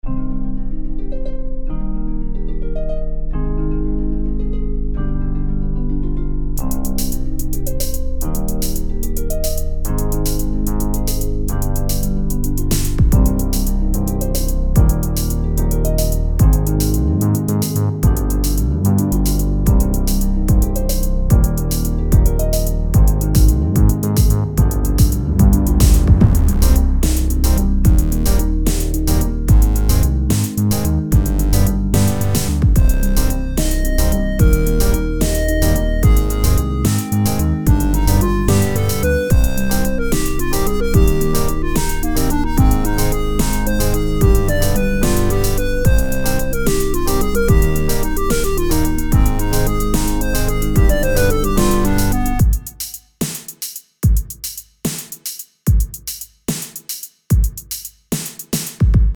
Just a little experiment - Electronic - Young Composers Music Forum
Just audio because, is hard to write a sheet of this. is not finished Btw it is a 6/8, and if it helps, the progresion is Im, IIIb, VIb, V in C minor.